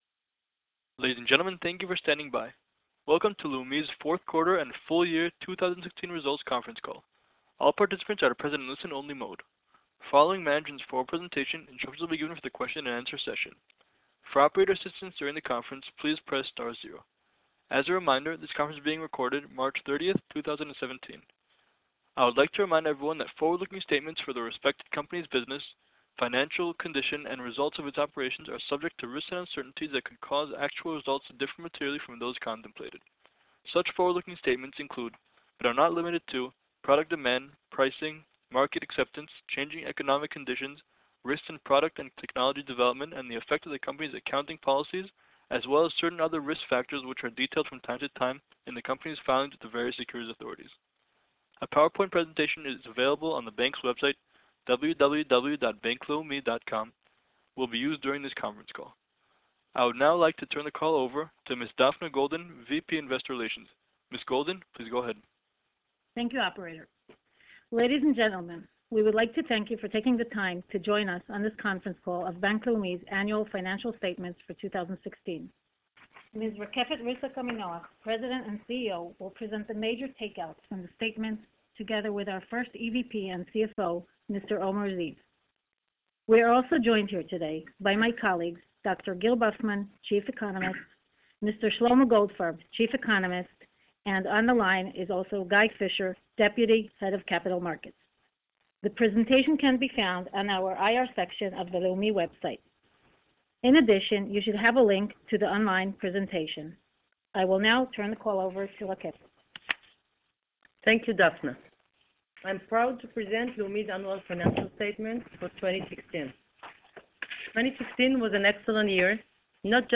Investor Presentation and Conference Call